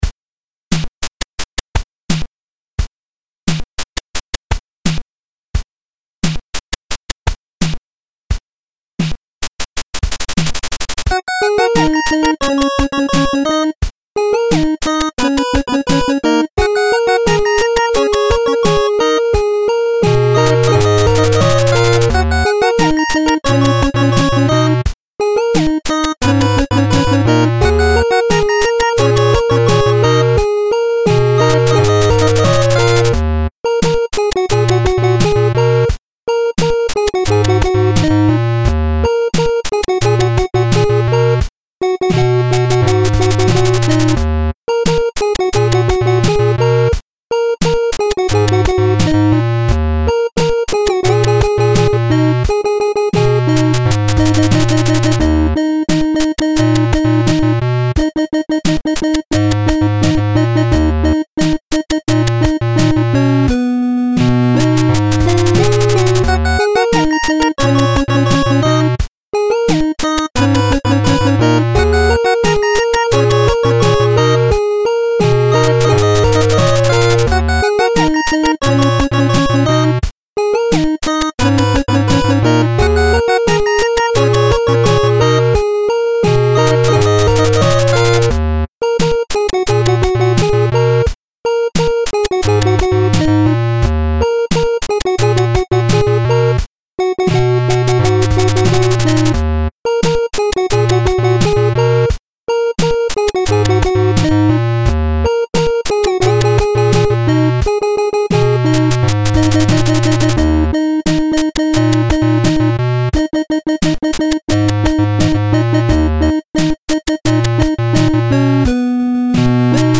8-bit cover
Filed under: Instrumental Cover | Comments (2)
Pretty groovy!